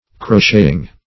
Crocheting - definition of Crocheting - synonyms, pronunciation, spelling from Free Dictionary
(sh[=a]d"); p. pr. & vb. n. Crocheting (-sh[=a]"[i^]ng).]
crocheting.mp3